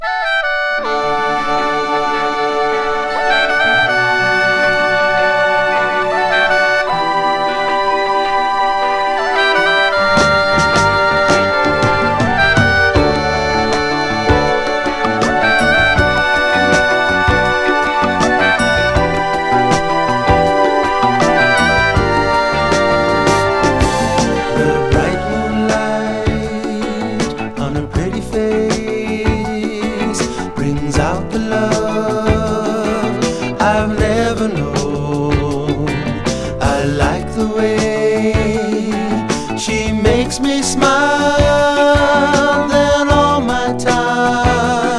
ポップ〜AOR風味漂う爽やかなハワイアン・アコースティック・デュオ
それもあってかこれまでより、リラックス&ウォーミングさアップな印象。ふたりのボーカル、ハーモニーも魅力的。
Rock, Pop, Hawaii　USA　12inchレコード　33rpm　Stereo